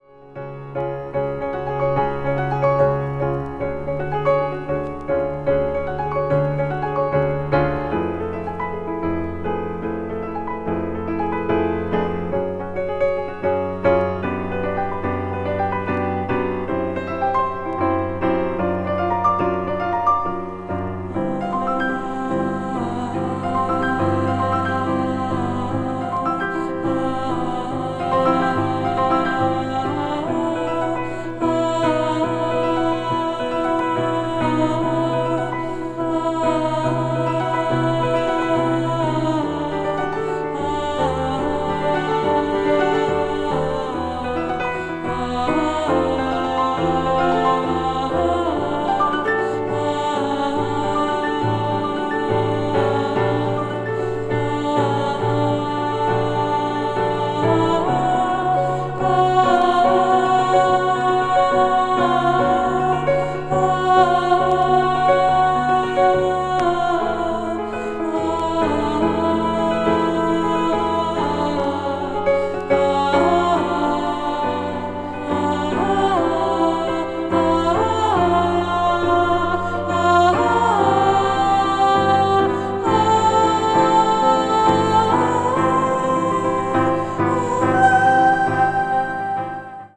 ambient jazz   contemporary jazz   deep jazz